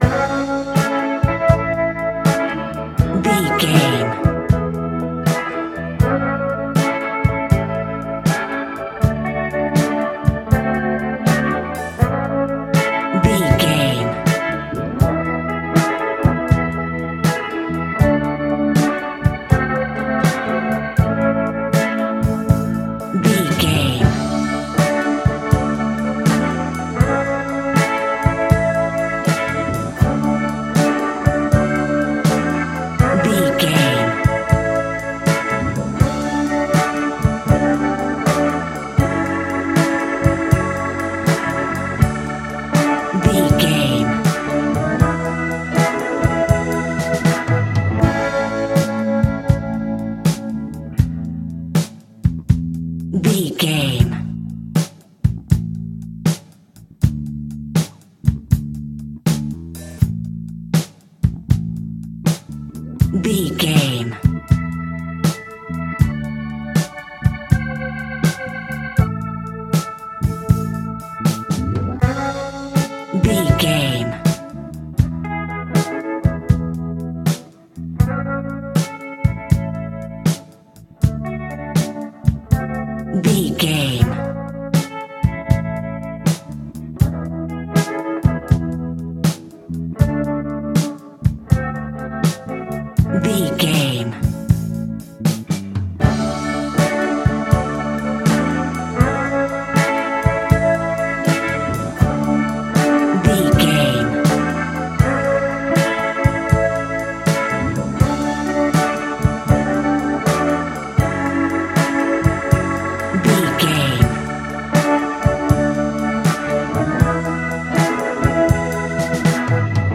Ionian/Major
funky
uplifting
bass guitar
electric guitar
organ
percussion
drums
saxophone
groovy